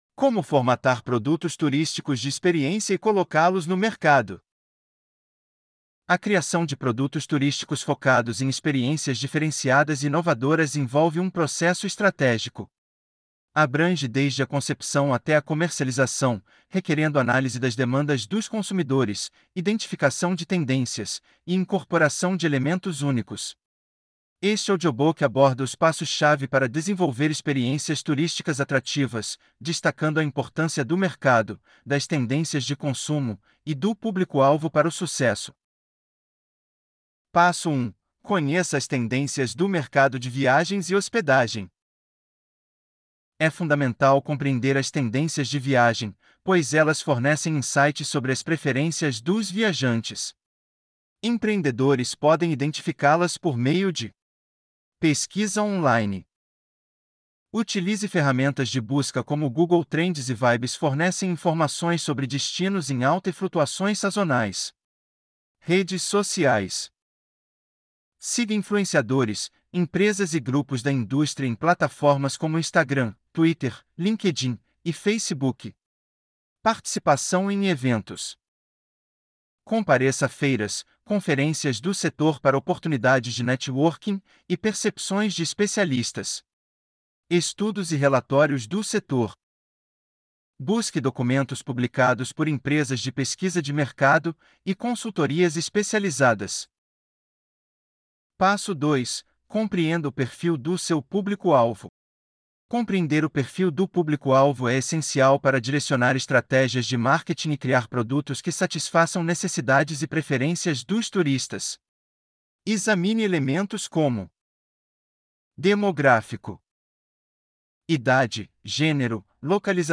Audiobook-como-formatar-produtos-turisticos-para-coloca-los-no-mercado.mp3